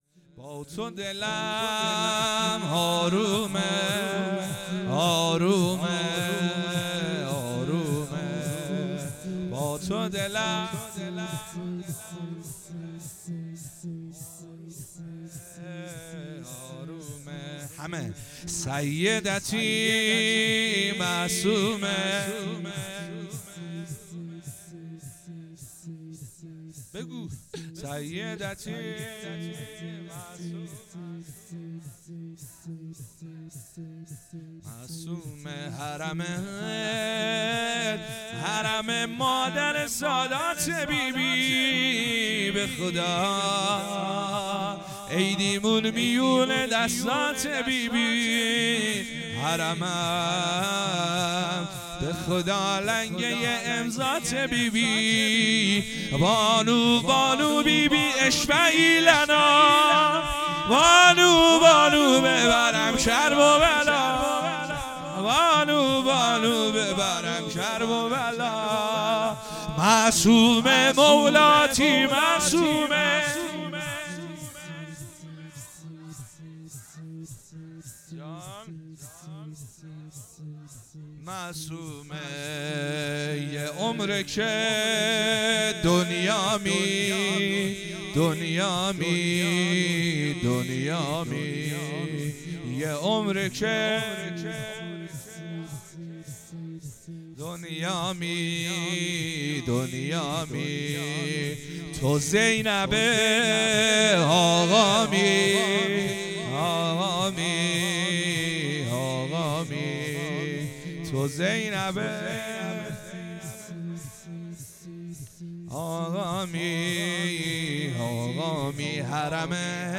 جشن ولادت حضرت فاطمه معصومه (س)